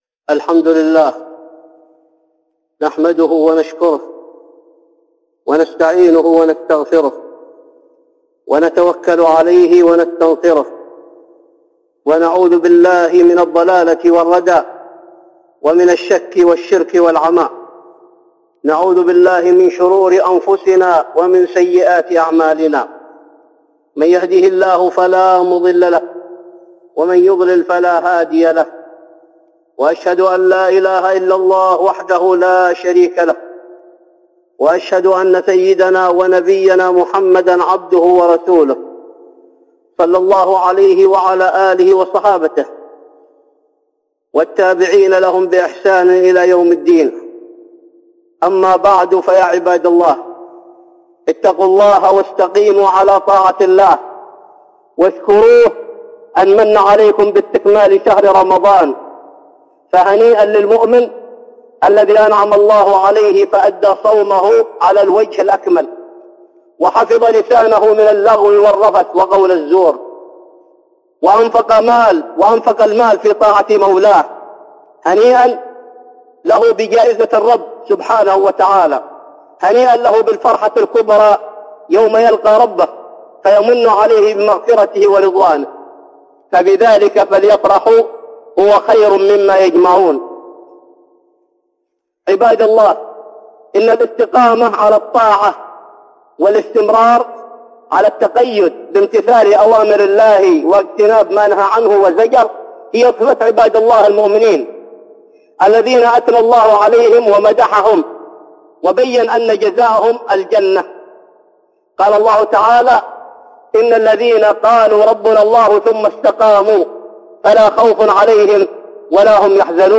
(خطبة جمعة) الاستقامة بعد رمضان